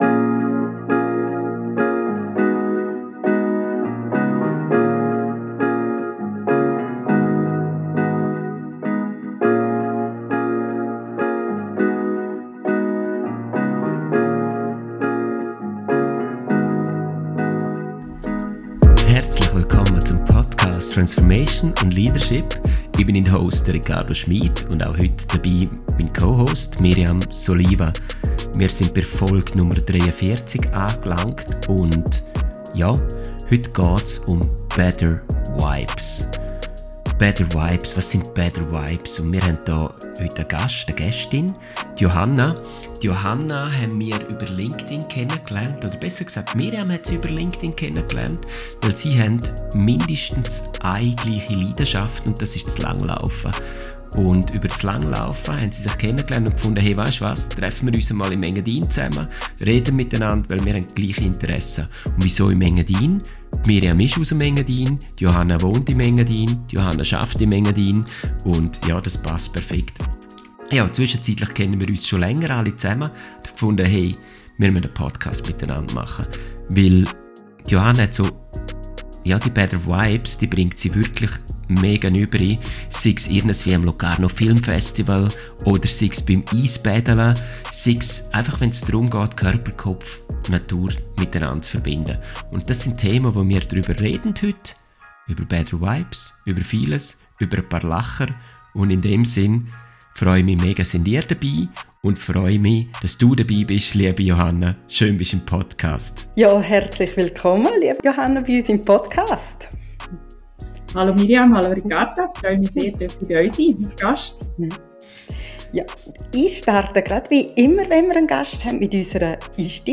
Schweizerdeutsch
Ein Gespräch.